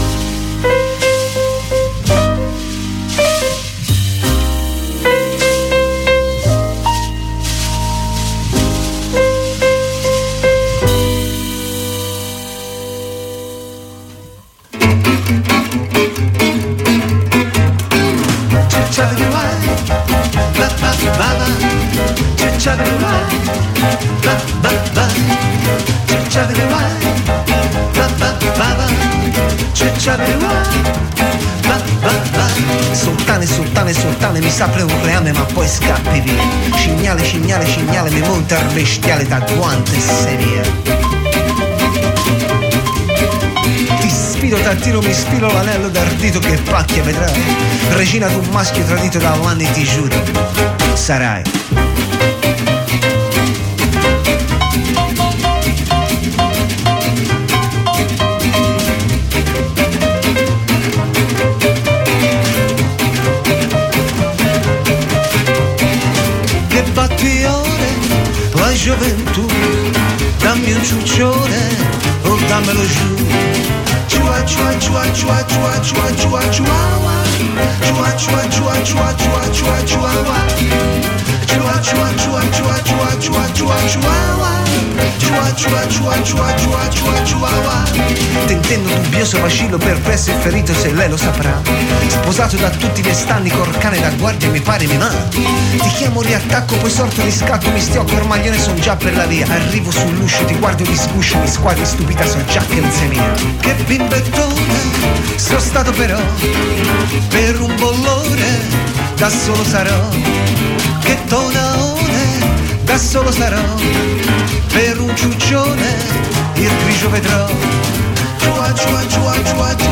Gatti Mézzi. Tour nei teatri. L'intervista negli studi di Controradio